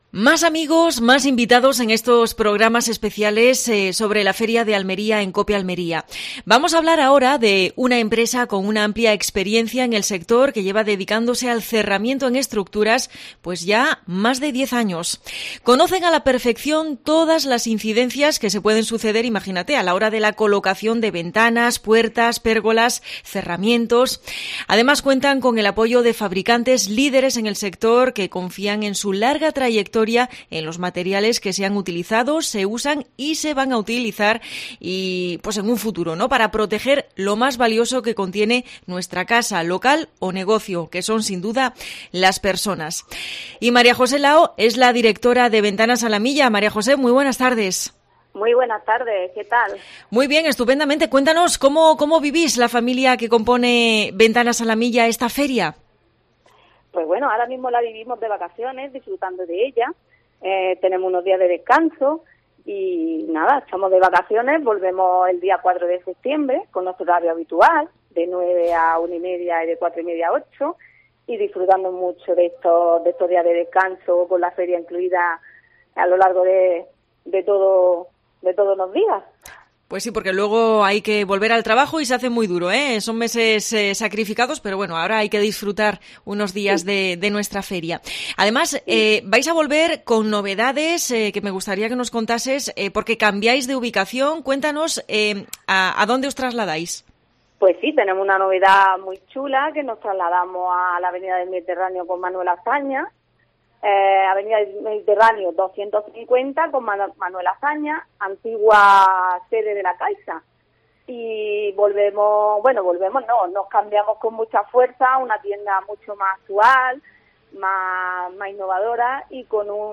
En los programas especiales de Feria, desde el Hotel Torreluz, entrevista